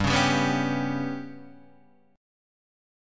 GMb5 chord {3 2 5 4 2 3} chord